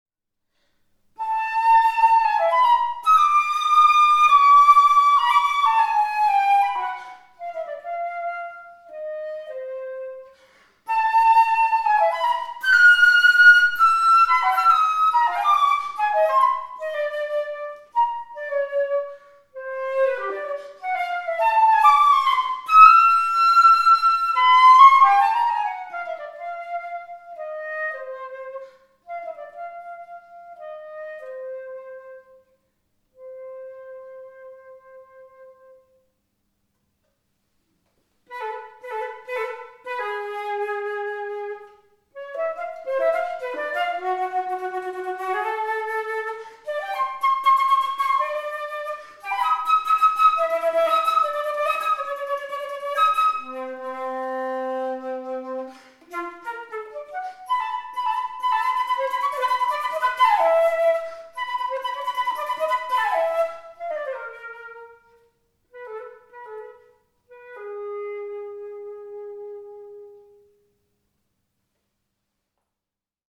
Materials Solid silver
With the head joint full inserted, it plays are a=443 Hz.